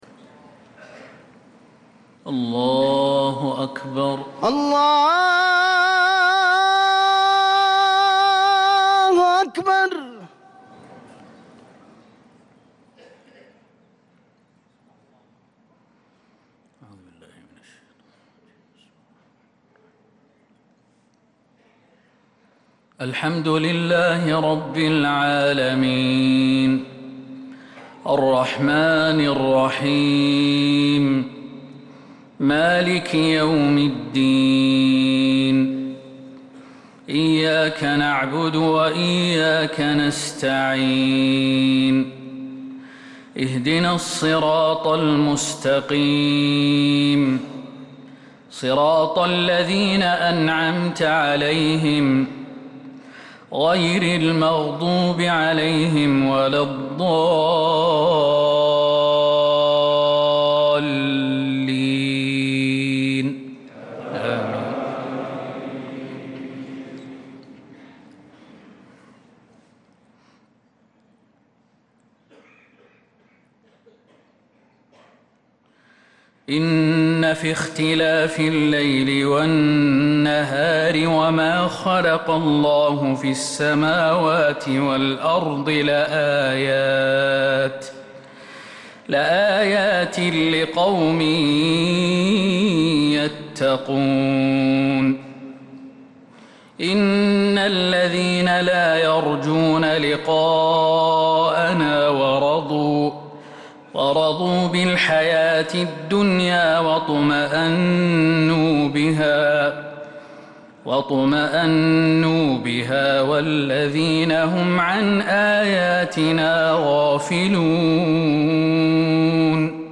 مغرب الأربعاء 7 محرم 1447هـ من سورة يونس 6-10 | Maghrib Prayer from Surat yunus 2-7-2025 > 1447 🕌 > الفروض - تلاوات الحرمين